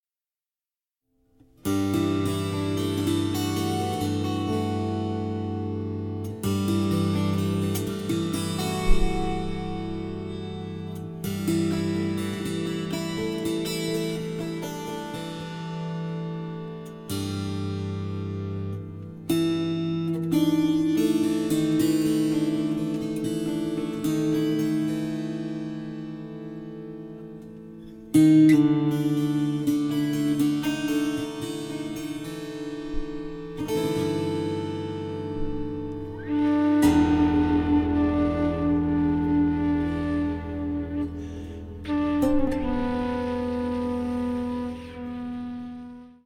Tenor and Soprano saxophones, Alto flute